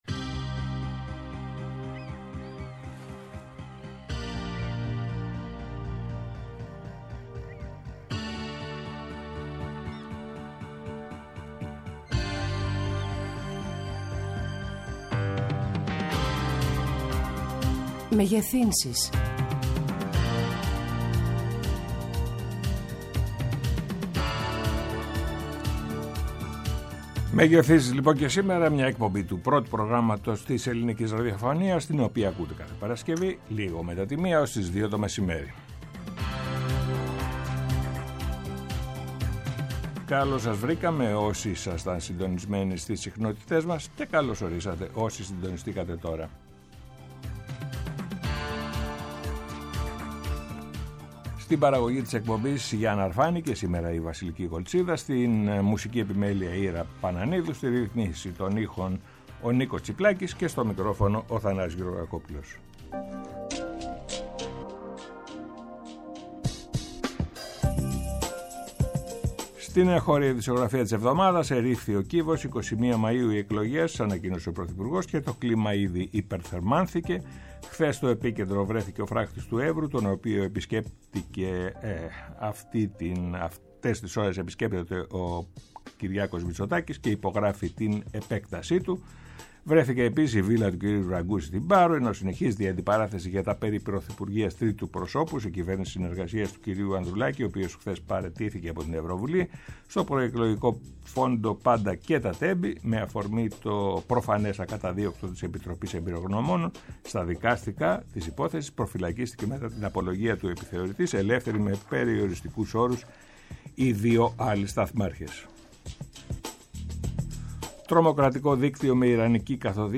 -ο Πάνος Τσακλόγλου , Υφυπουργός Εργασίας και Κοινωνικών Υποθέσεων
Καλεσμένοι τηλεφωνικά
Συνεντεύξεις